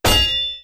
Melee Weapon Attack 19.wav